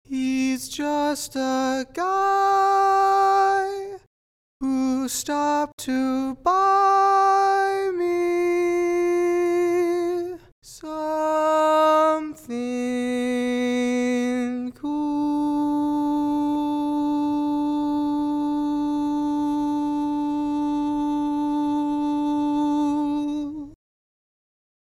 Key written in: E♭ Major
Each recording below is single part only.